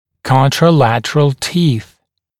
[ˌkɔntrə’lætərəl tiːθ][ˌконтрэ’лэтэрэл ти:с]аналогичные зубы, находящиеся на противоположных сторонах одного зубного ряда